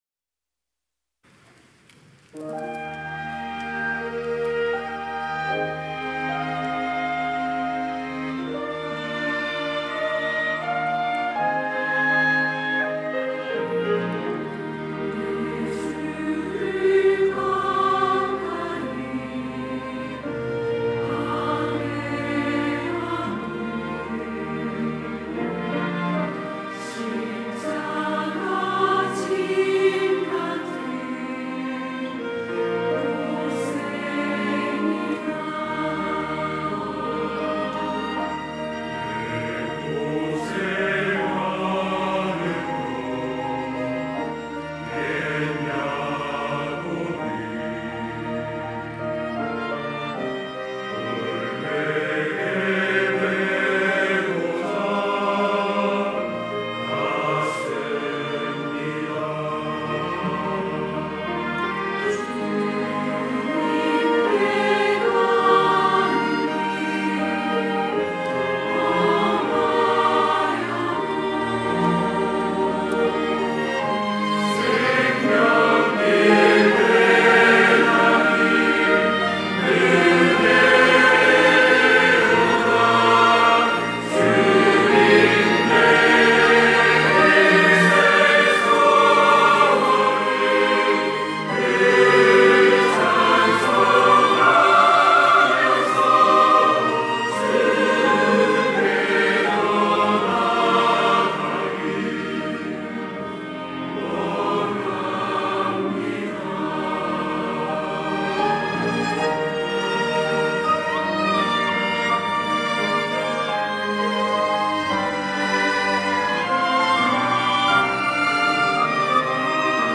호산나(주일3부) - 내 주를 가까이 하게 함은
찬양대 호산나